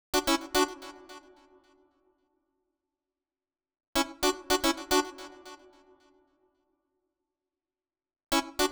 35 Synth PT1.wav